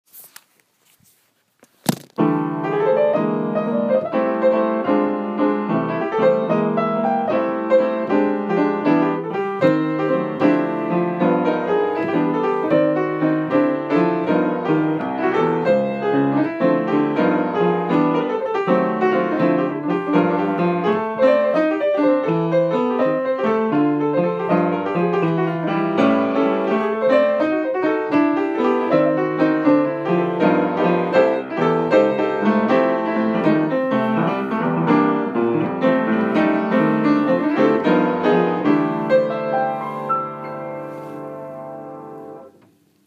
and I just felt like sharing a little jazz waltz I wrote for my amazing